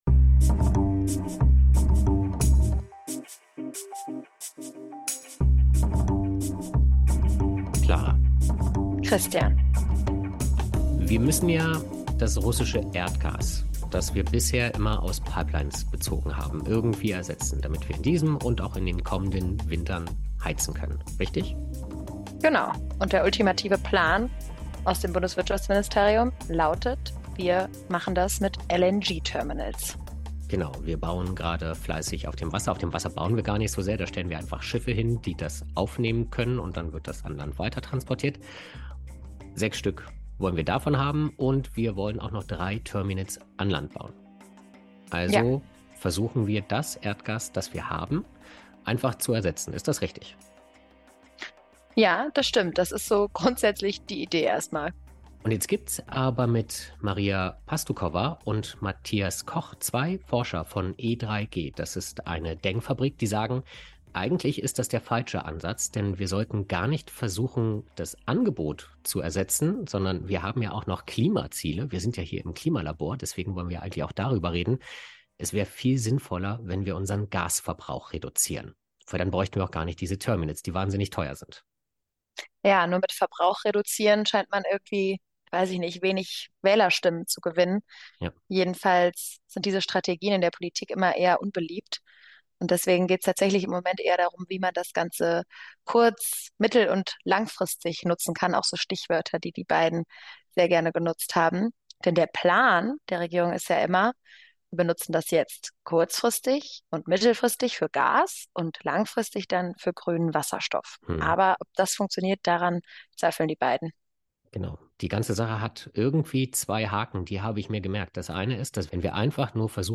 Dann bewertet das "Klima-Labor" bei Apple Podcasts oder Spotify Das Interview als Text?